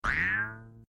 KART_tossBanana.ogg